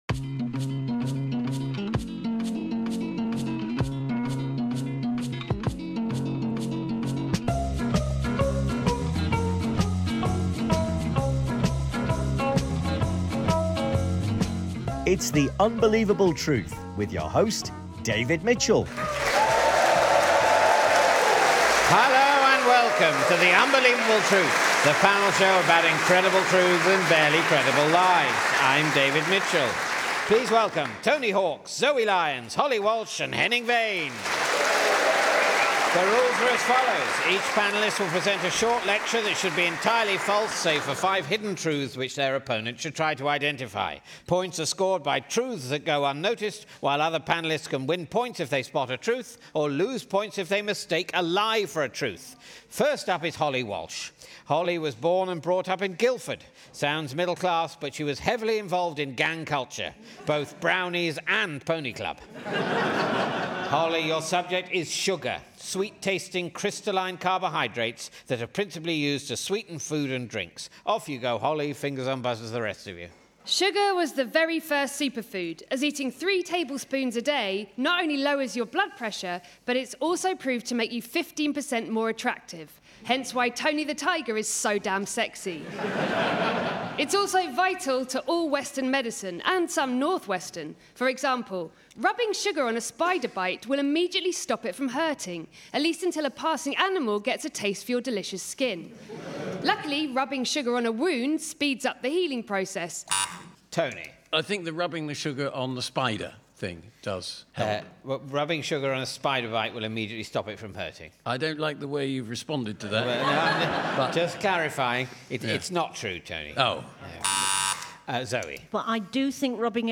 The Unbelievable Truth Series 32 - 5. Sugar, Saints, Spiders and Heavy Metal Play episode January 19 28 mins Bookmarks Episode Description David Mitchell hosts the panel game in which four comedians are encouraged to tell lies and compete against one another to see how many items of truth they're able to smuggle past their opponents. Holly Walsh, Henning Wehn, Zoe Lyons and Tony Hawks are the panellists obliged to talk with deliberate inaccuracy on subjects as varied as sugar, saints, spiders and heavy metal.